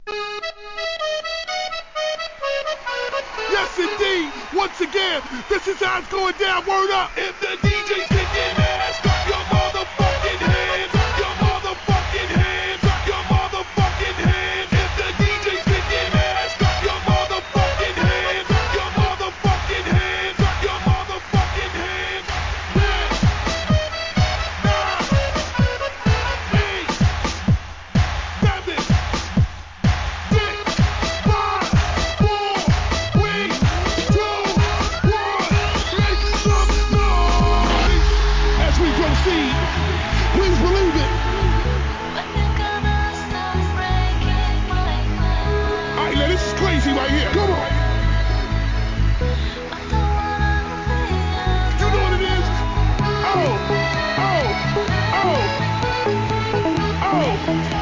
1. HIP HOP/R&B